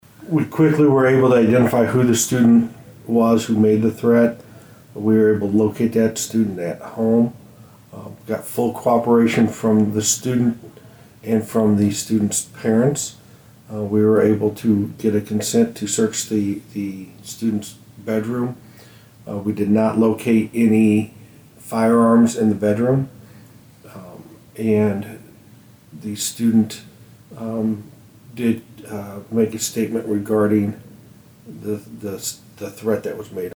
Here’s Humboldt Police Chief Joel Sanders on what the initial investigation uncovered.